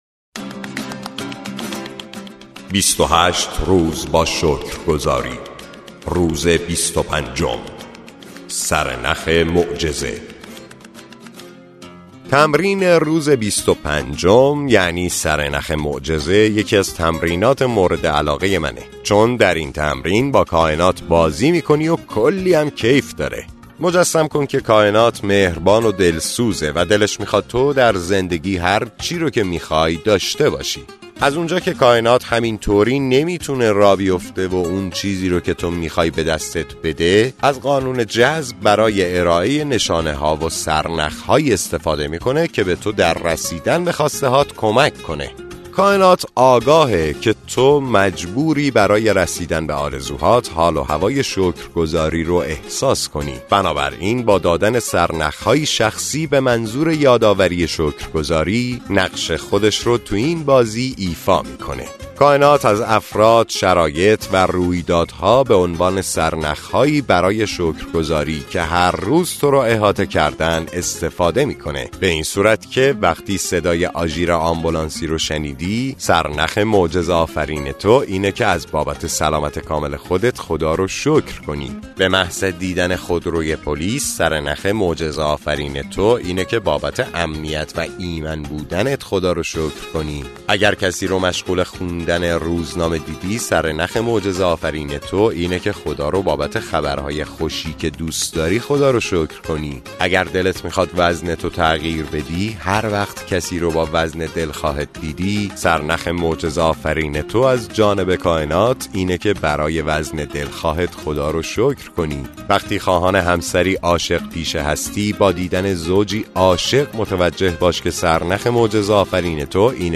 کتاب صوتی معجزه شکرگزاری